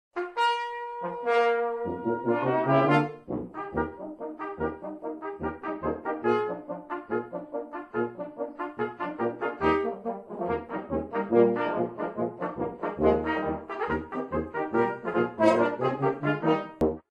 Einstimmiger Chorgesang zu 86 deutschen Volksliedern.
Probenmitschnitt